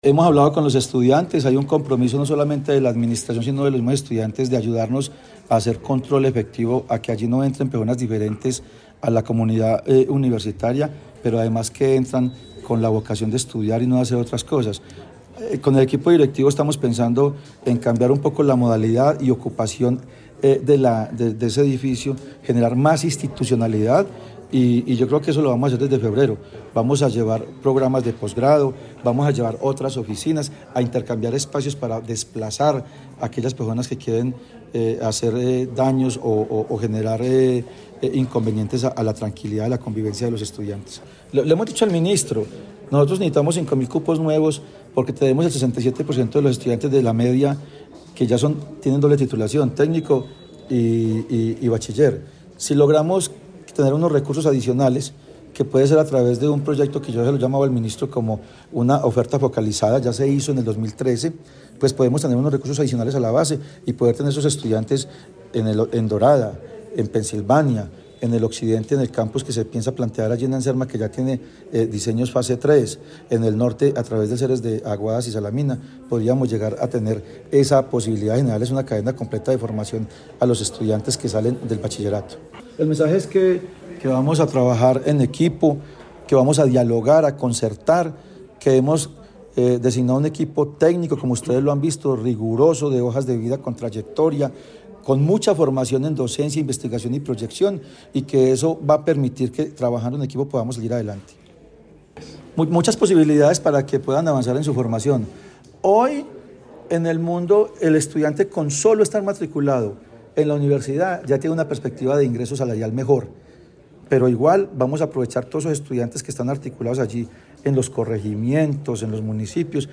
Algunas ideas principales del discurso del rector fueron: